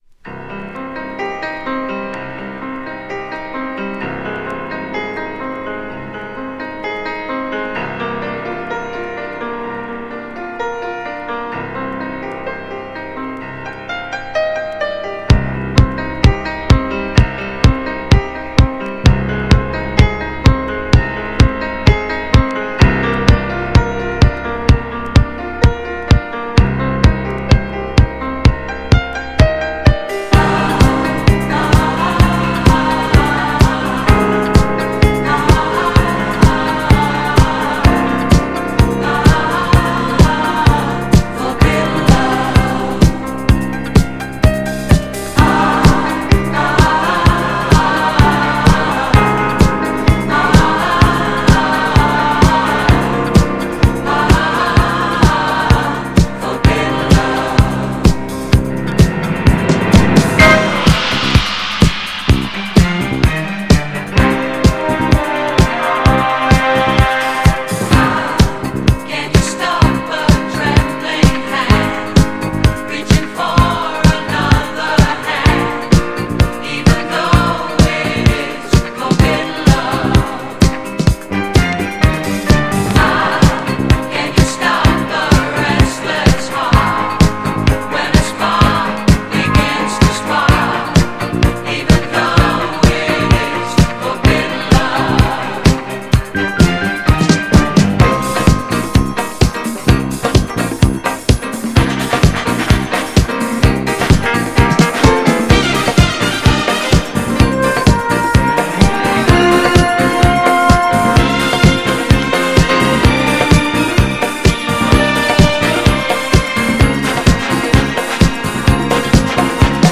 GENRE Dance Classic
BPM 116〜120BPM
エモーショナル # ストリングス # ハートウォーム # ミュンヘンDISCO # 熱いボーカル